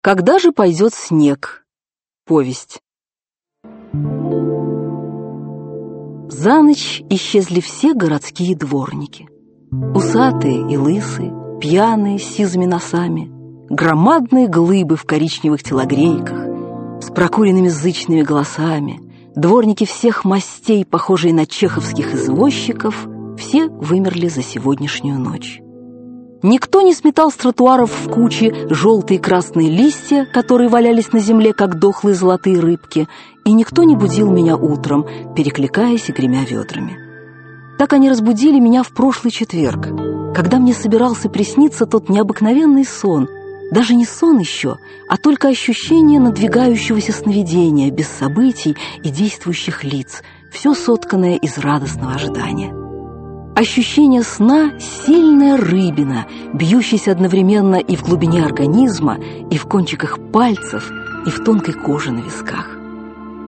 Дина Рубина. Когда же пойдет снег?.. Радиоверсия спектакля Московского театра юного зрителя Дина Рубина.